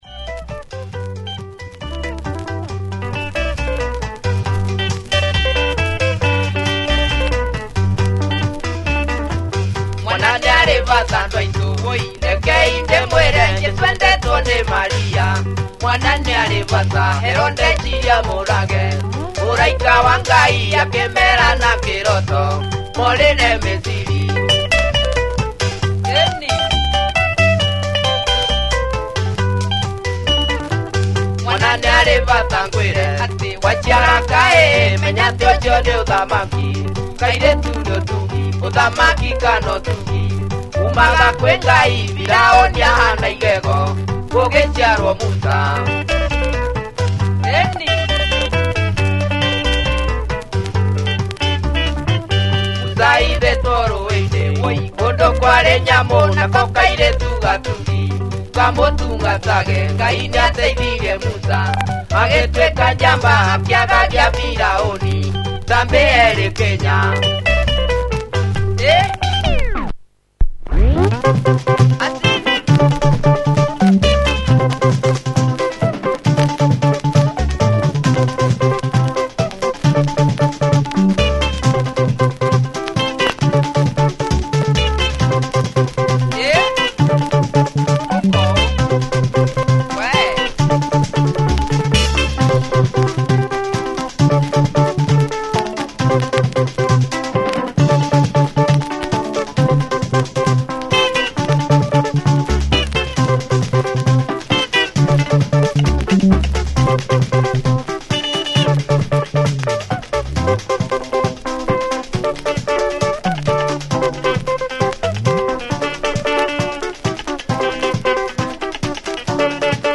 Nice funky benga
Some serious funky guitar riffin midway’